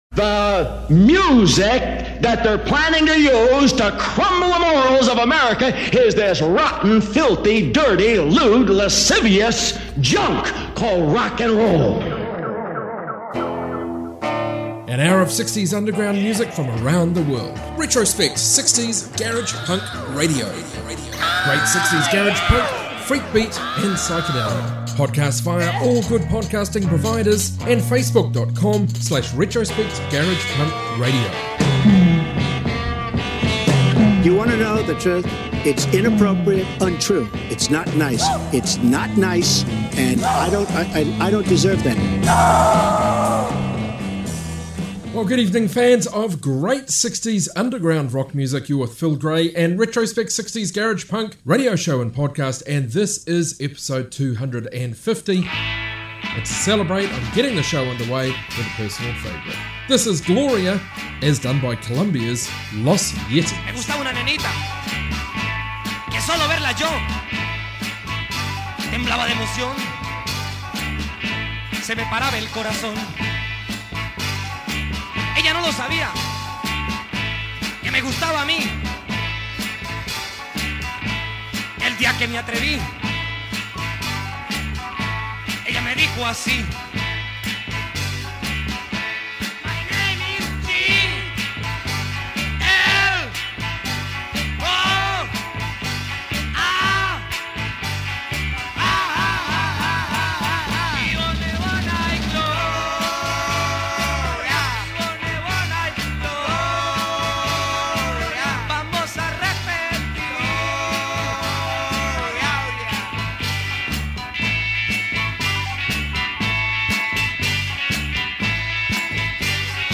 garage rock garage punk proto-punk freakbeat and psych